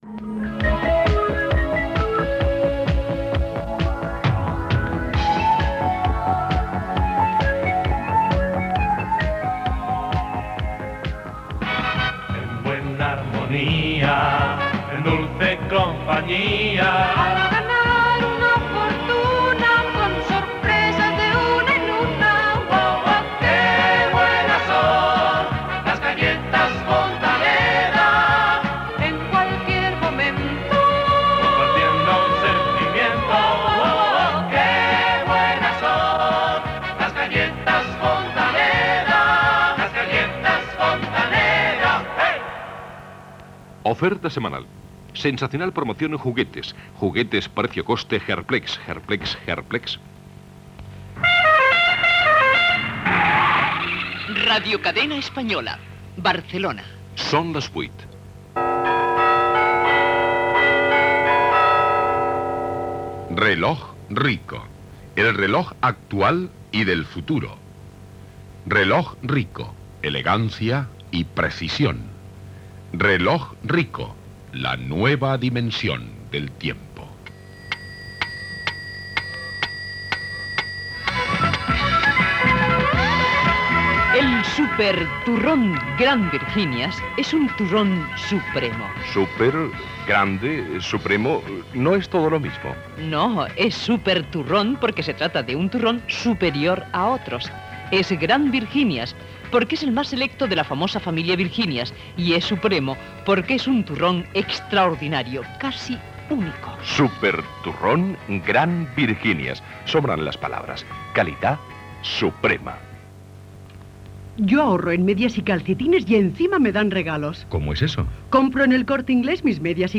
Publicitat, indicatiu, hora, publicitat i inici del programa.
Musical